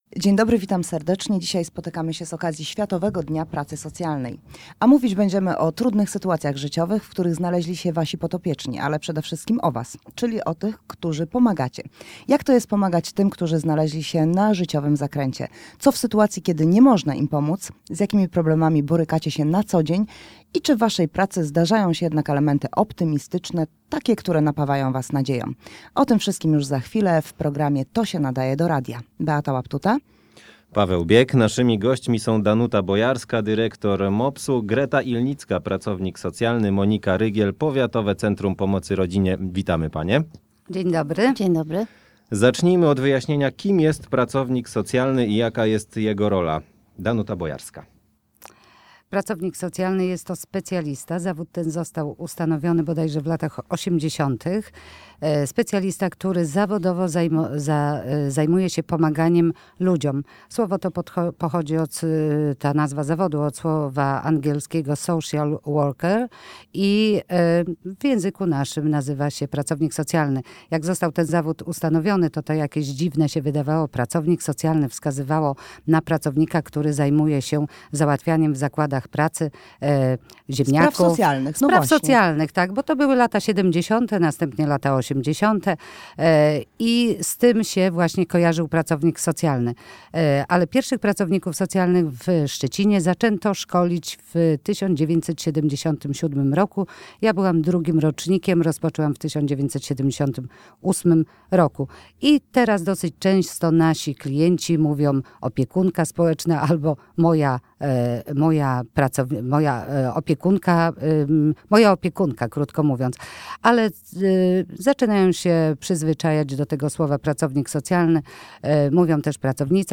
W naszym studio gościliśmy